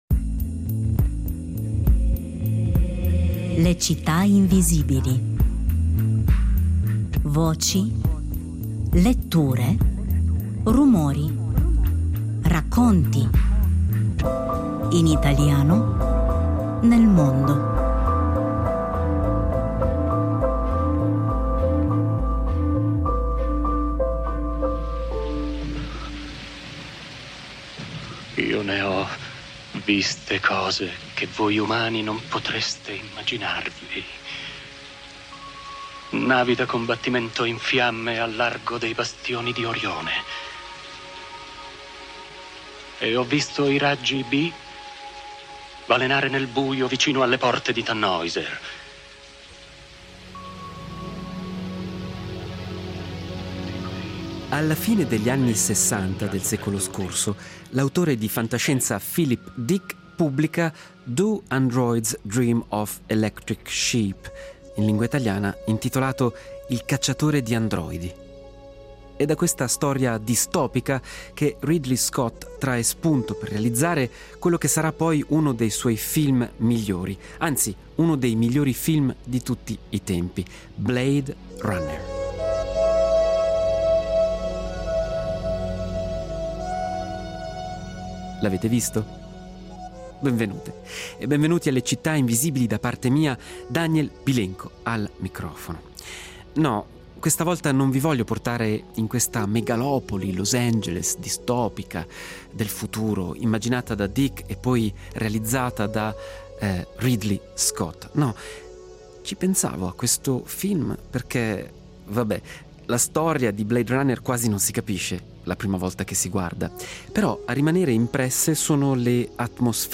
Le atmosfere sonore sono realizzate ad hoc